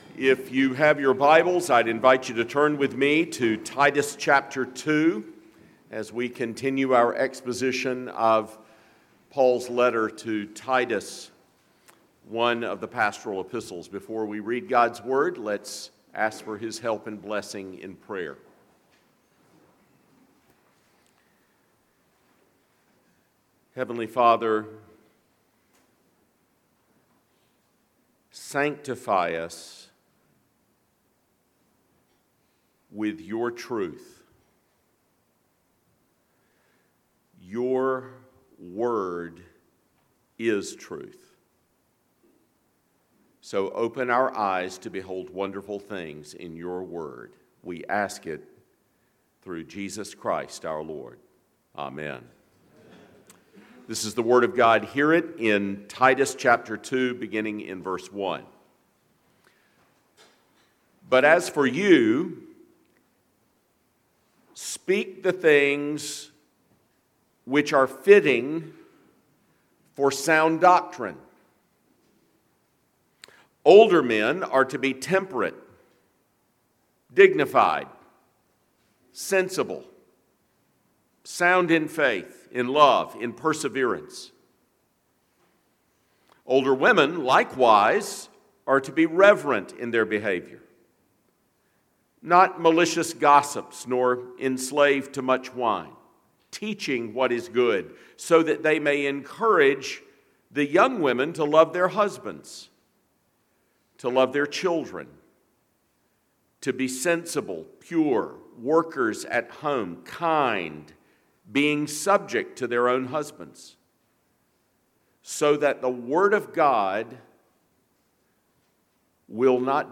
The Pastoral Epistles: Worship Service II – Grace, Has a Purpose